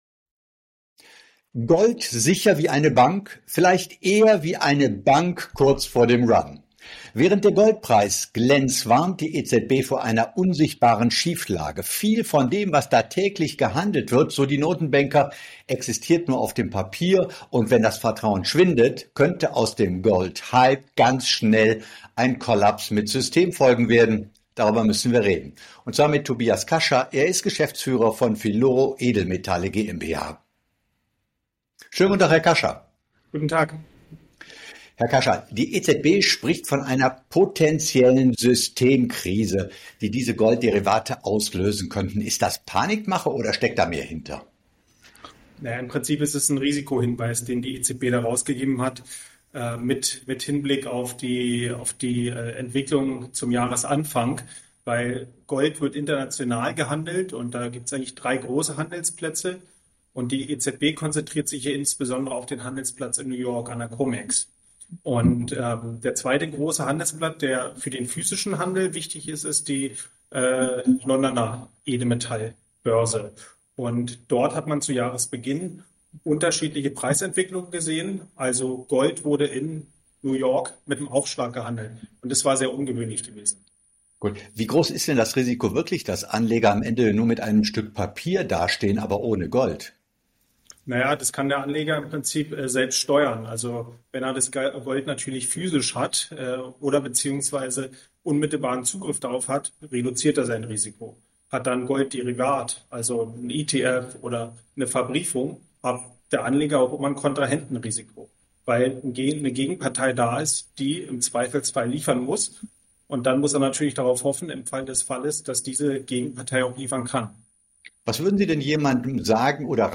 Ein Gespräch über Vertrauen, Liquidität und die Frage: Wie sicher